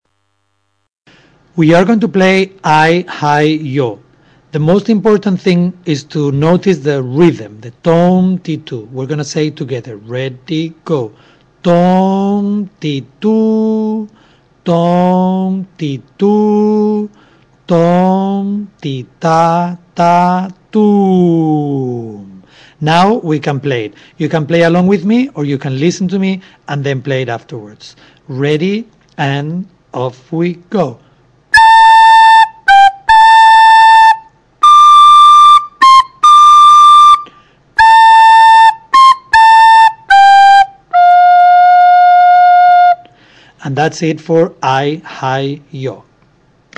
Chinese Songs on Recorder
We learned that one of the most important musical elements of Chinese music is the pentatonic scale: a musical scale made up of five sounds that are equivalent to the ones we sing (Do, Re, Mi, So and La).
We have been learning to play Chinese folk songs on our recorders.